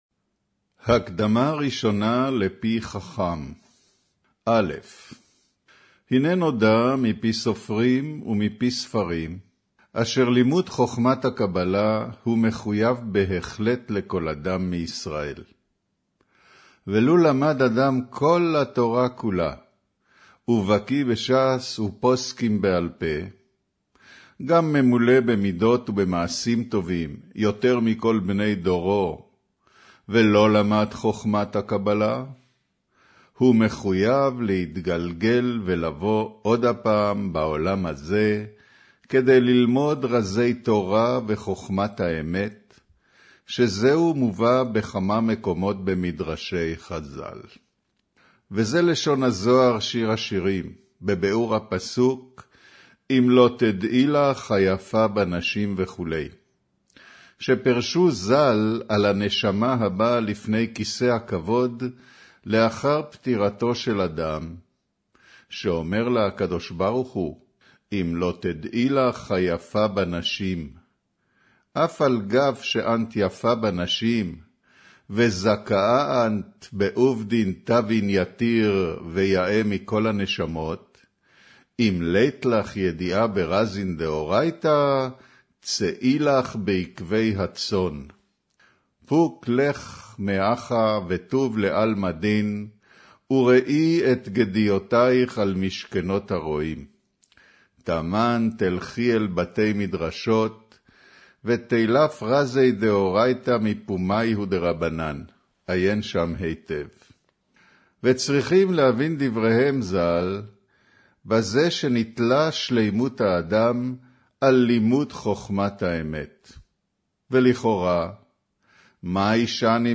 אודיו - קריינות הקדמה ראשונה לפי חכם